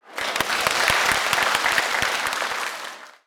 applause-b.wav